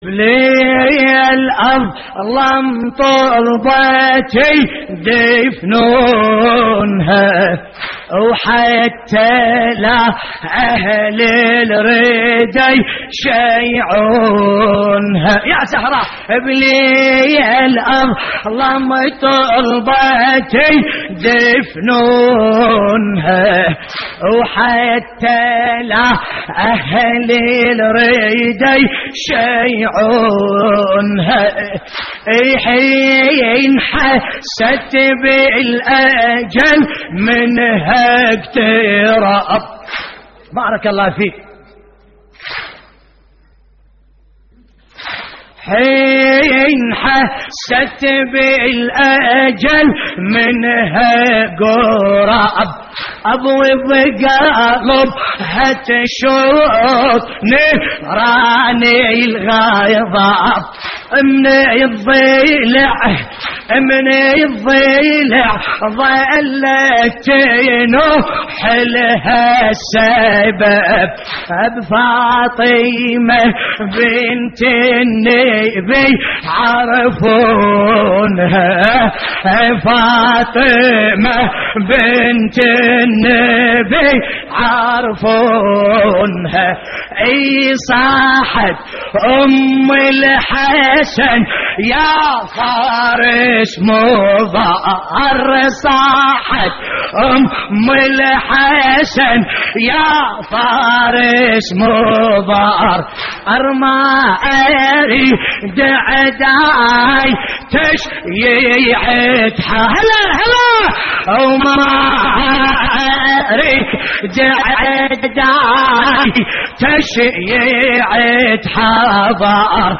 تحميل : بليل أظلم طلبت يدفنونها حتى لا أهل الردى يشيعونها / الرادود باسم الكربلائي / اللطميات الحسينية / موقع يا حسين